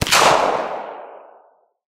Gun_Caliber22_14.wav